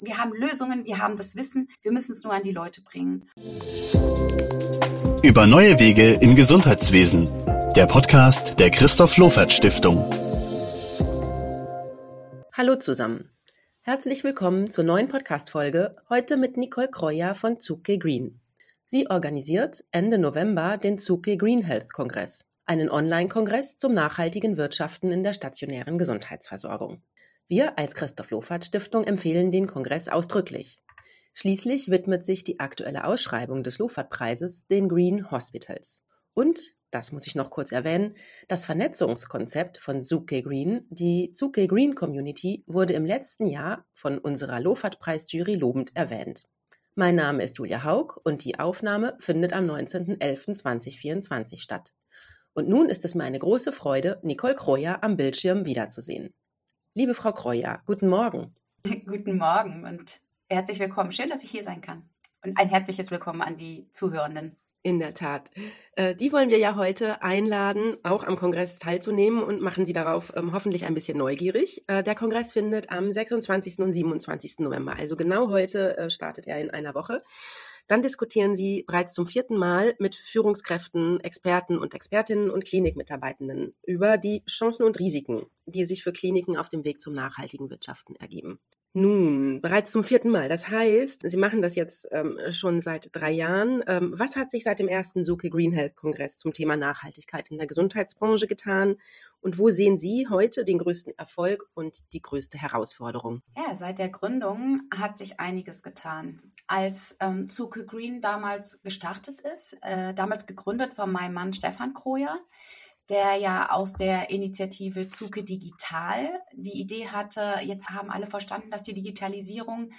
Das Interview im Überblick: